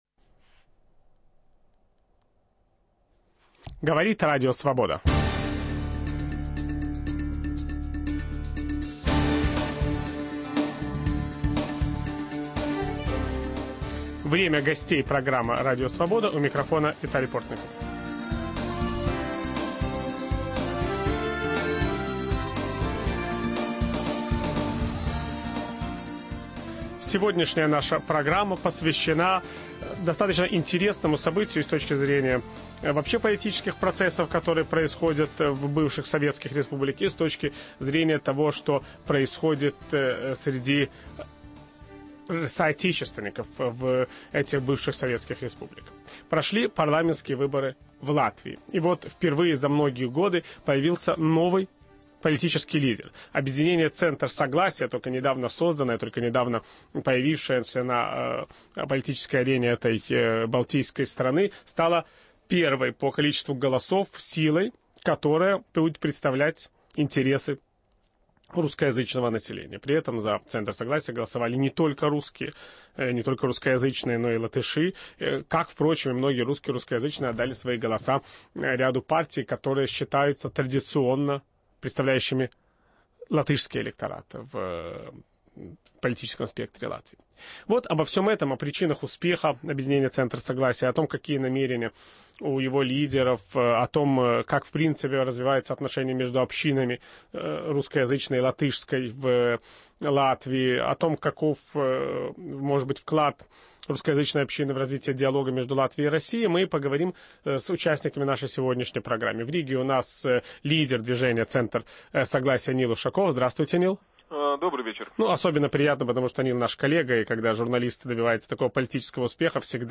"Новые русские" в латвийском парламенте. В программе участвуют лидер объединения "Центр согласия" Нил Ушаков